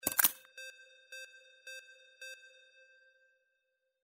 Stereo sound effect - Wav.16 bit/44.1 KHz and Mp3 128 Kbps